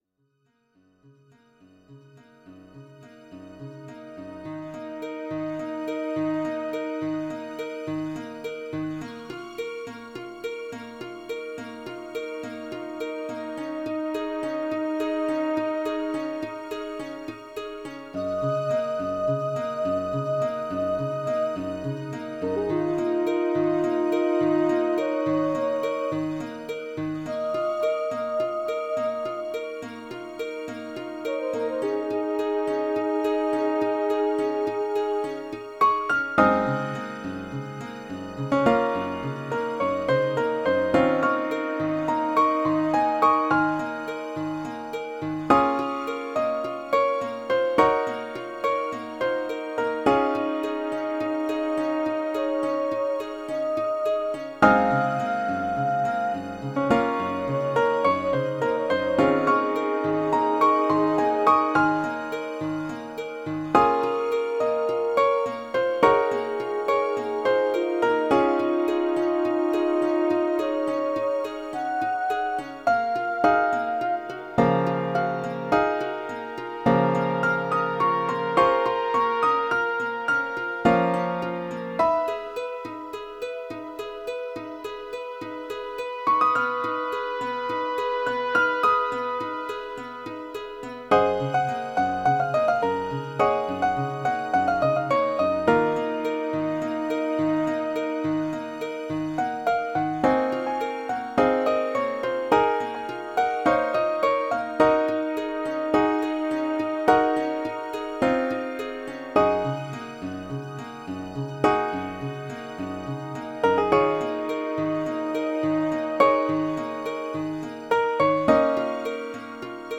atmospheric II_1.ogg